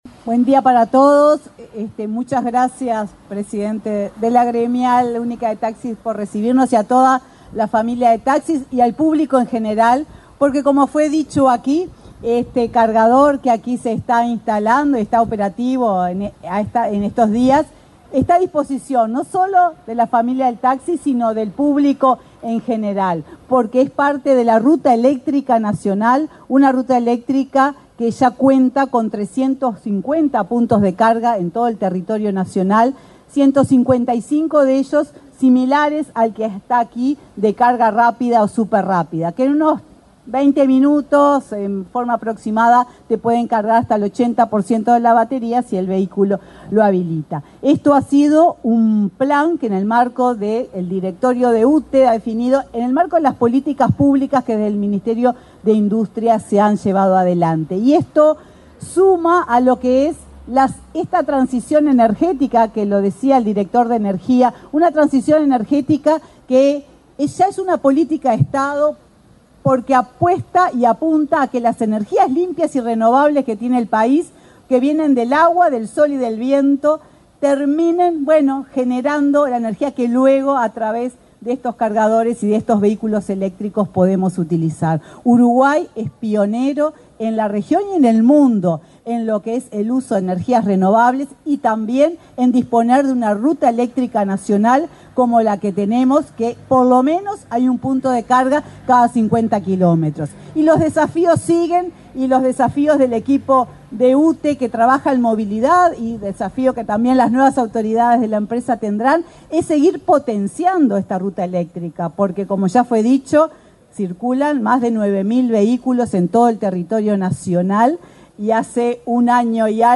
Palabras de autoridades en acto de UTE
Palabras de autoridades en acto de UTE 25/02/2025 Compartir Facebook X Copiar enlace WhatsApp LinkedIn La presidenta de la UTE, Silvia Emaldi, y el director nacional de Energía, Christian Nieves, participaron en el acto de inauguración de cargadores eléctricos, instalados en la sede de la Gremial Única del Taxi.